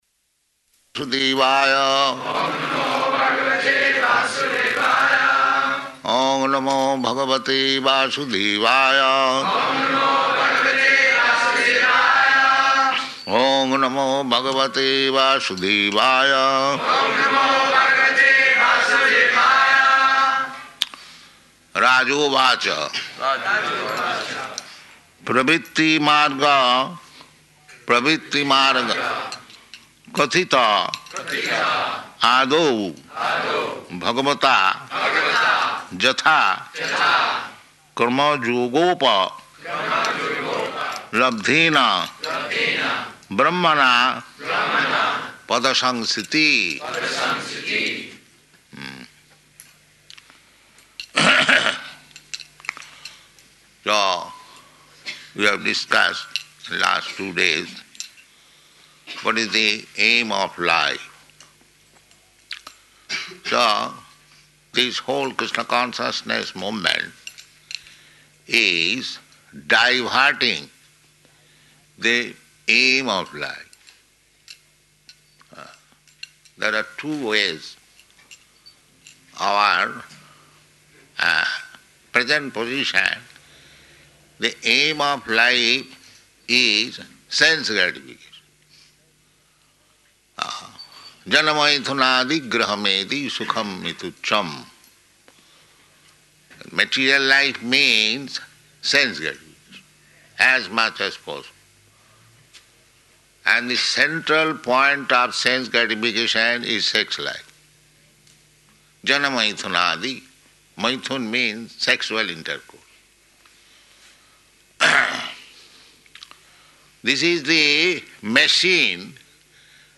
Śrīmad-Bhāgavatam 6.1.1 --:-- --:-- Type: Srimad-Bhagavatam Dated: May 21st 1975 Location: Melbourne Audio file: 750521SB.MEL.mp3 Prabhupāda: ...sudevāya. Oṁ namo bhagavate vāsudevāya.
[devotees chant responsively] rajovaca pravṛtti-mārgaḥ kathita ādau bhagavatā yathā krama-yogopālabdhena brahmaṇā padasaṁsṛtiḥ [ SB 6.1.1 ] Hmm.